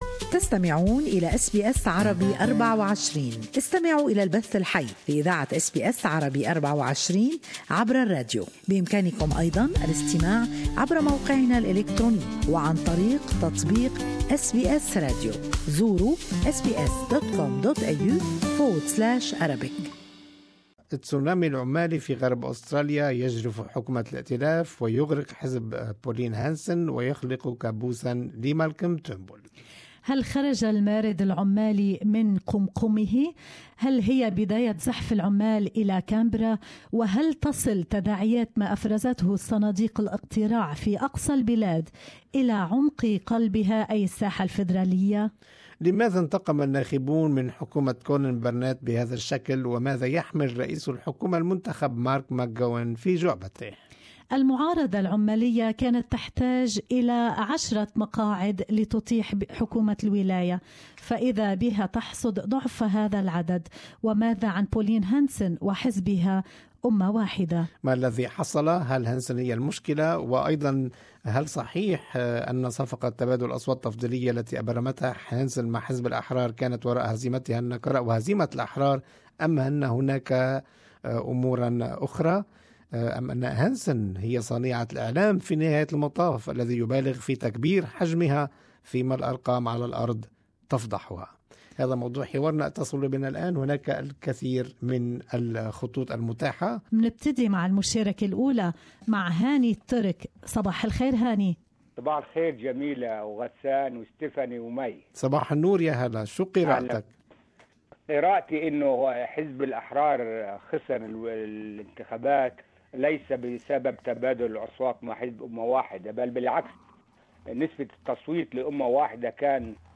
Good Morning Australia listeners share their opinions.